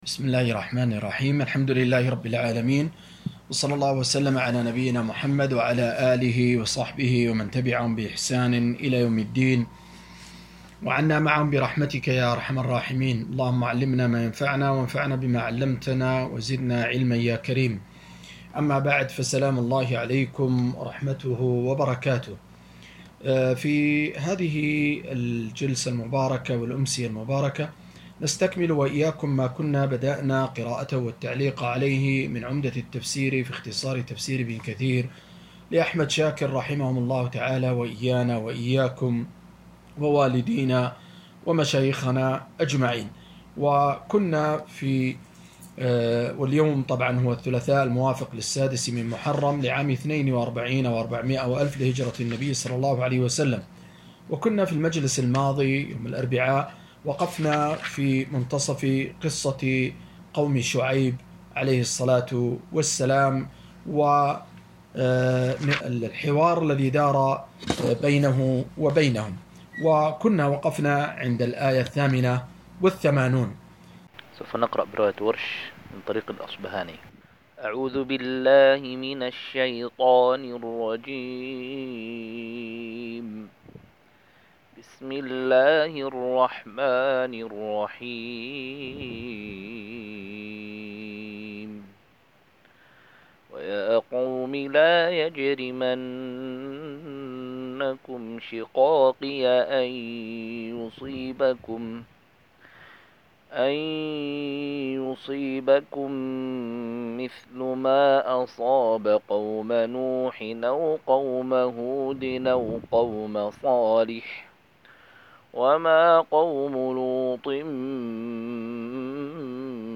219- عمدة التفسير عن الحافظ ابن كثير رحمه الله للعلامة أحمد شاكر رحمه الله – قراءة وتعليق –